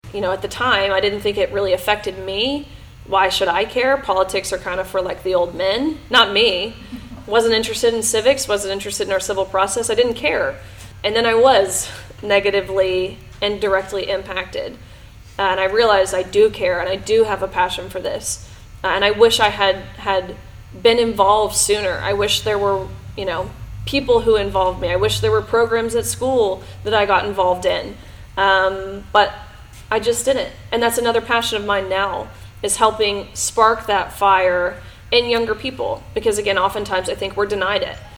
Gaines was the keynote speaker at the event, sharing her story and encouraging young leaders to get involved with the issues that affect them everyday. She says that speaking to people is something she’s grown passionate about.